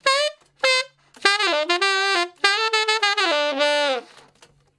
066 Ten Sax Straight (D) 10.wav